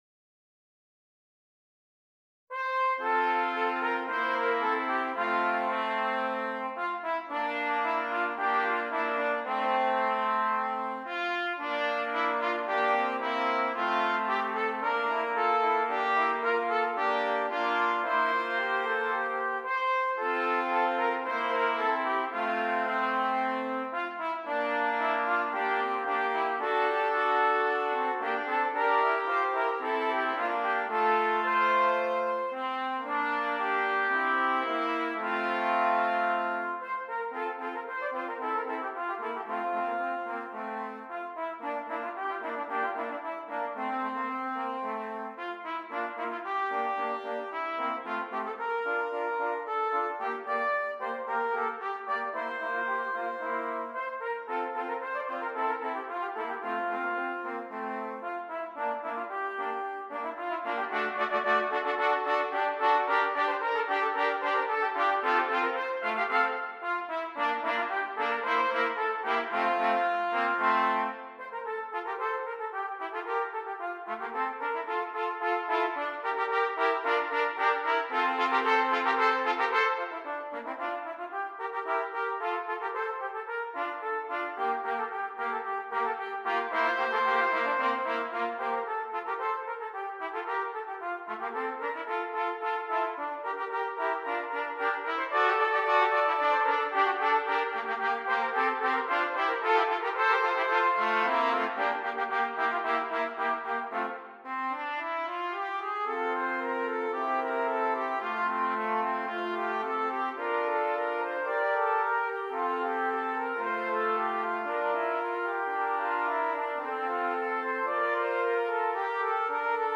3 Trumpets